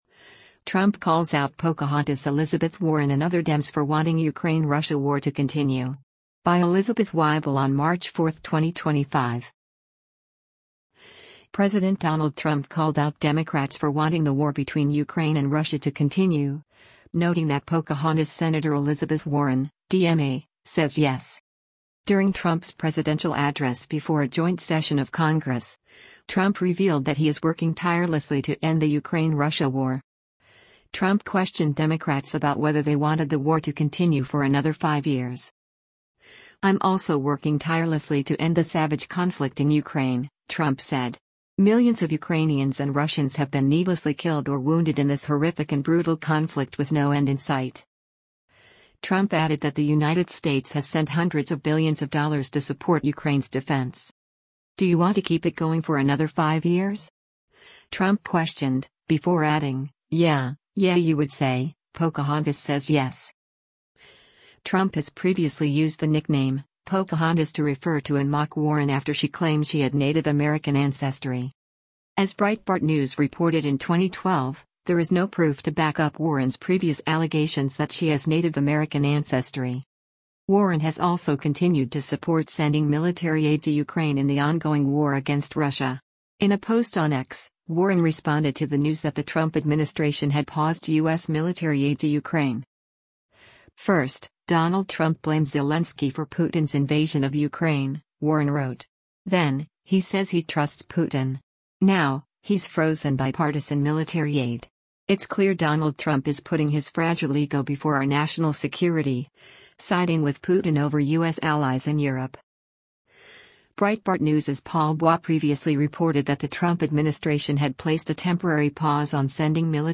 During Trump’s presidential address before a joint session of Congress, Trump revealed that he is “working tirelessly to end” the Ukraine-Russia war.